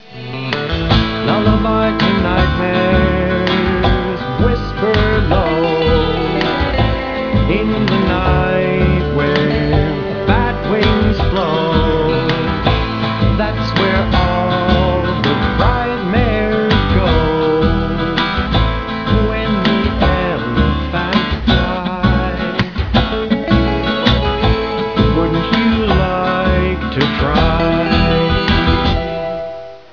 (8-bit / Mono / 30 sec / 330K)